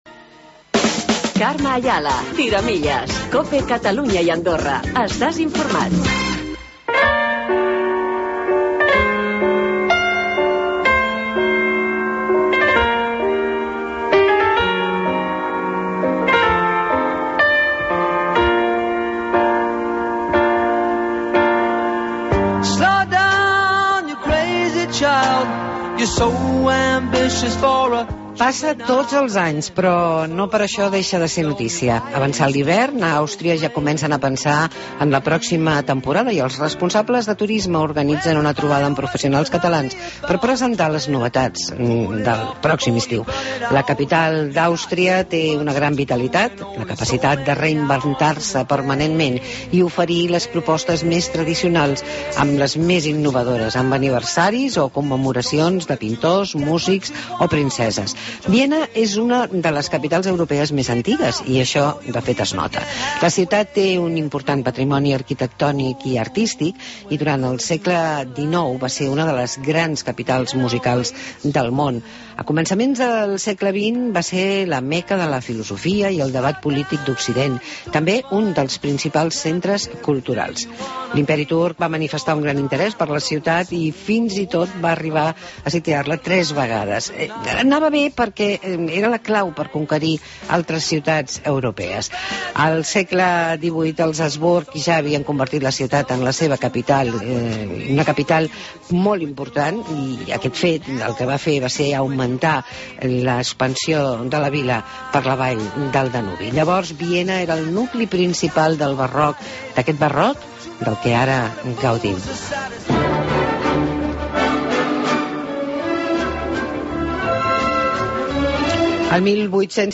Visita a Viena amb un reportatge enfocat als centres culturals de la ciutat